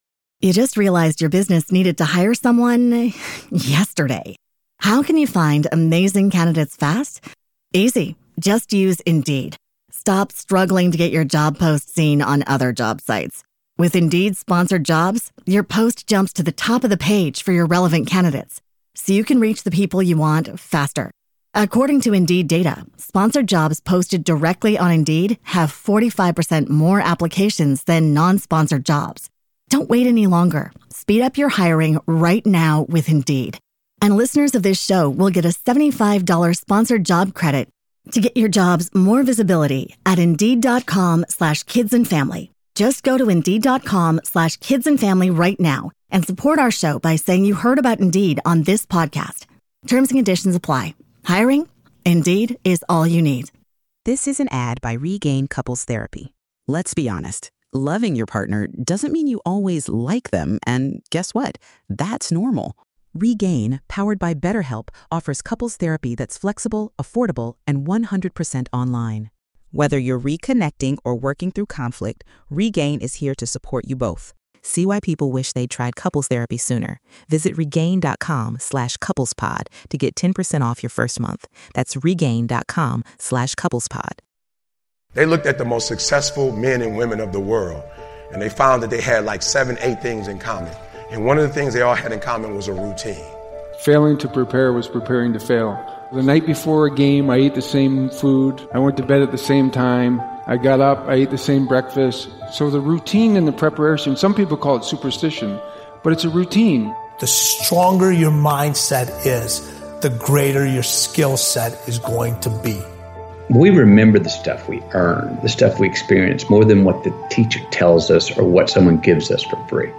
Speaker: Eric Thomas